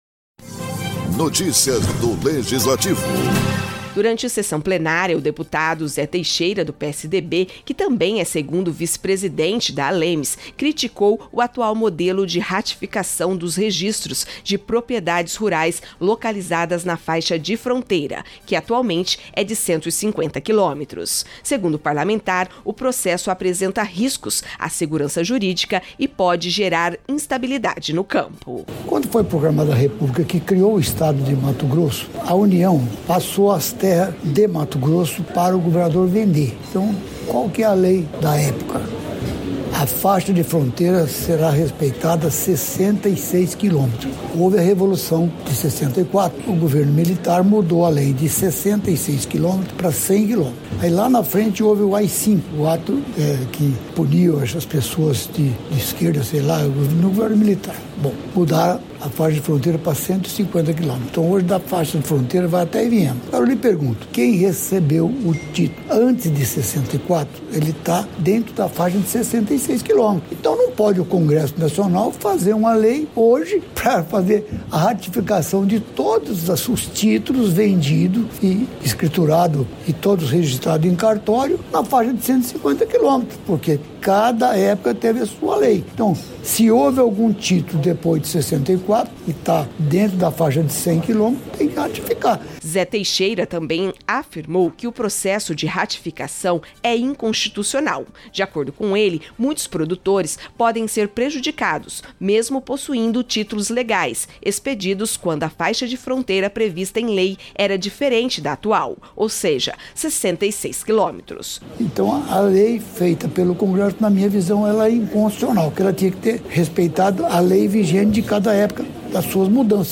O deputado estadual Zé Teixeira (PSDB), que também é 2º vice-presidente da Assembleia Legislativa de Mato Grosso do Sul (ALEMS),usou a tribuna da Casa de Leis para falar dos impactos do atual modelo de ratificação fundiária em áreas da faixa de fronteira